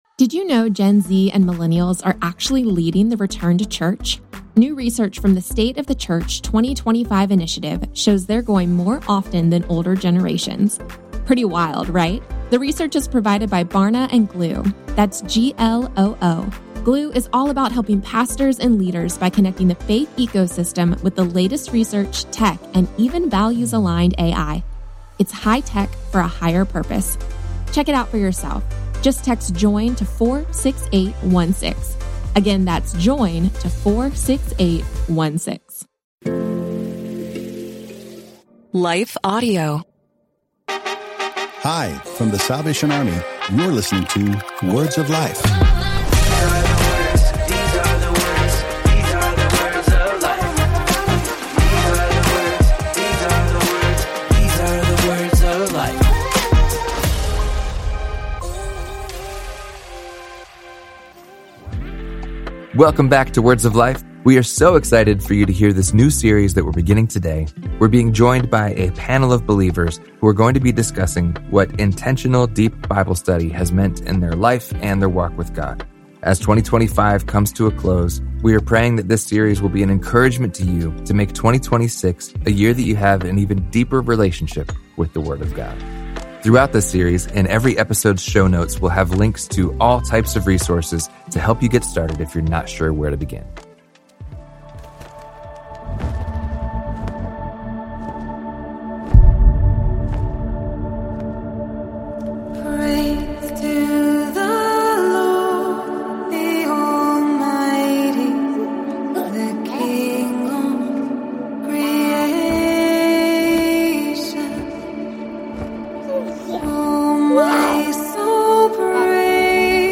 The Bible’s Influence on Faith, Family, and Culture — A Panel Conversation